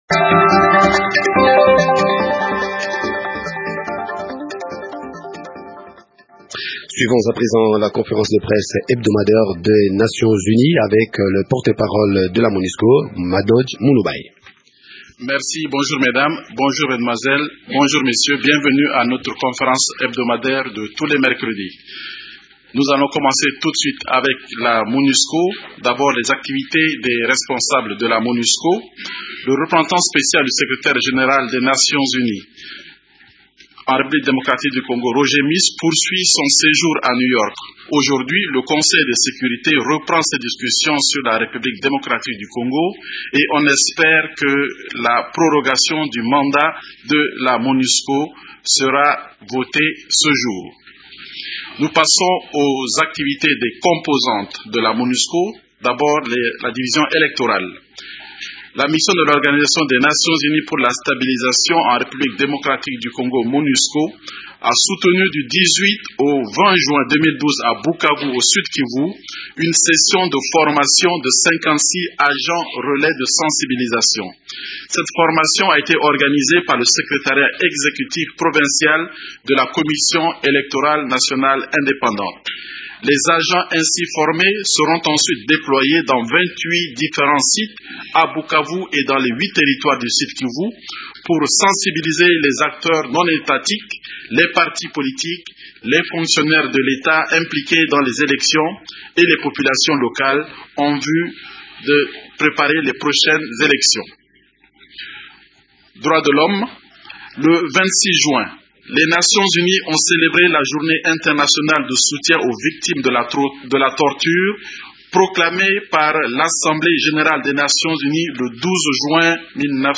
Une vue de la salle de conférence de la Monusco, pendant le point de presse hebdomadaire à Kinshasa.